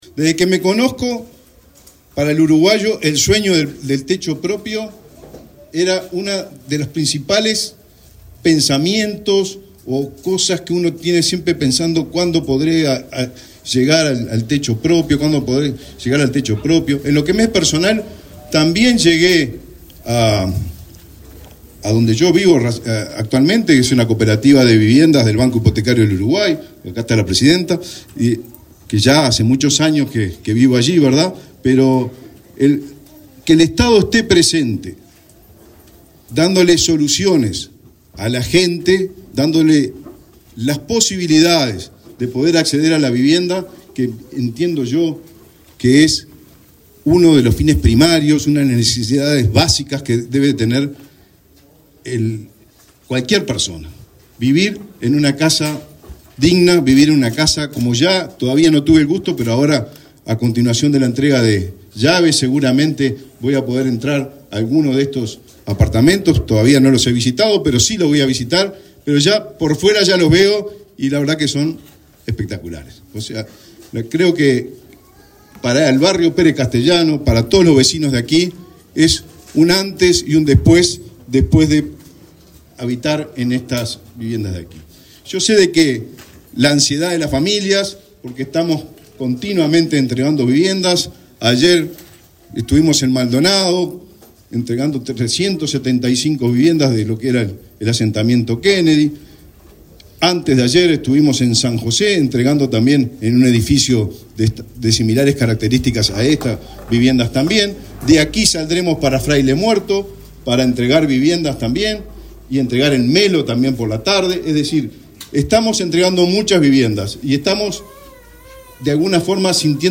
Palabras del ministro de Vivienda, Raúl Lozano
El ministro de Vivienda, Raúl Lozano, participó en el acto de entrega de 40 viviendas de un edificio sito en Olmedo y Arrotea, en Montevideo.